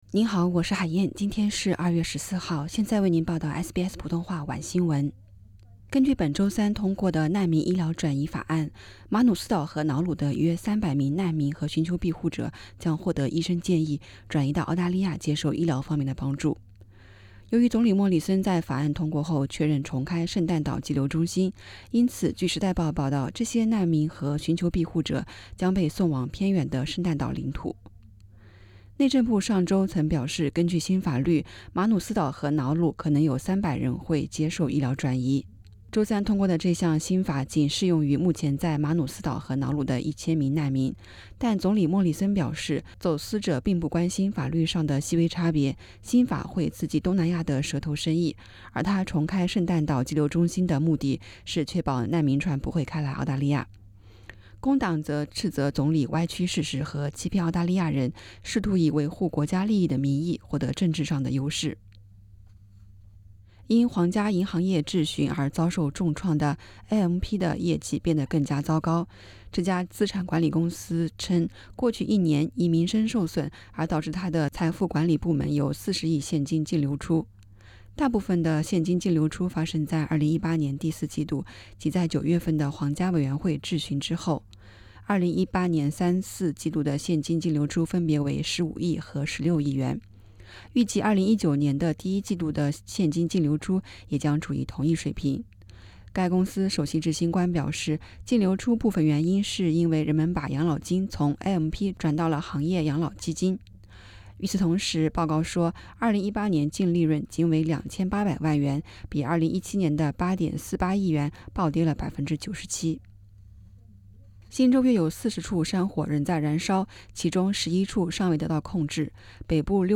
SBS晚新闻（2月14日）